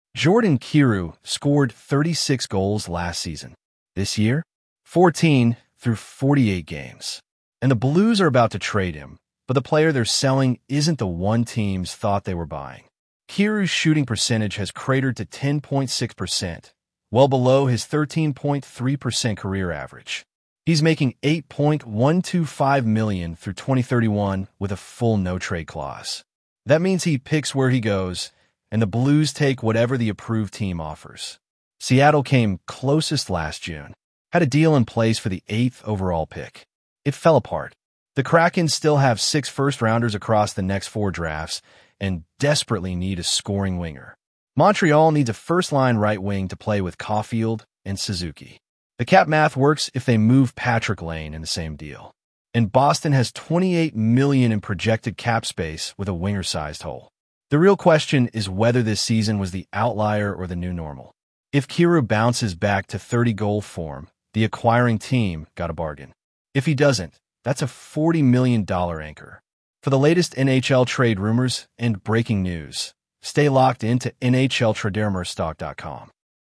AI Voice